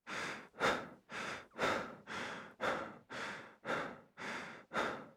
Breathing.wav